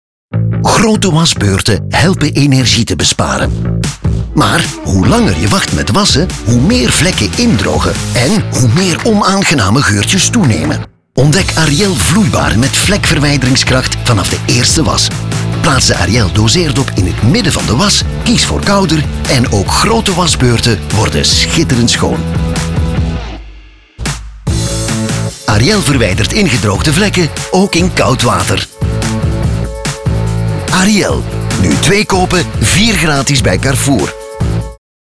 Distinctive, Mature, Warm
Commercial